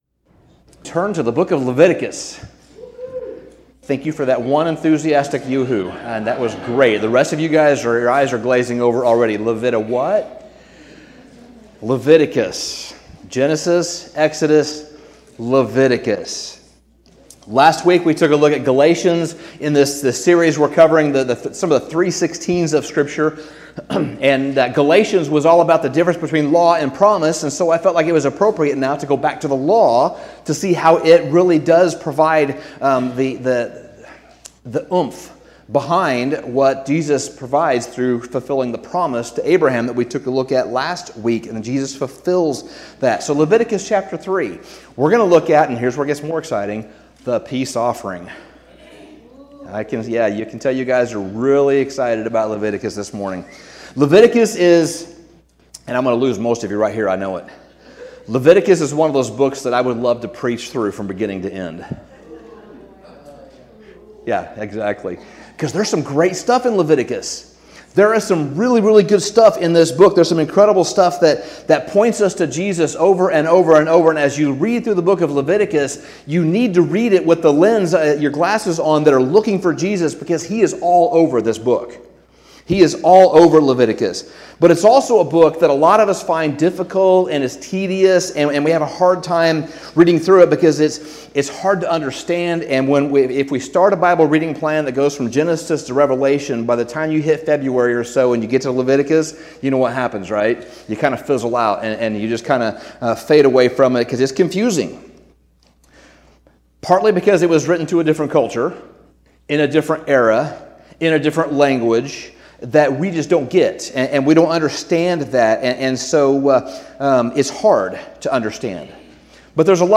Sermon Summary When most of us hear "Leviticus" we almost immediately tune out, because we have a hard time reading through this book.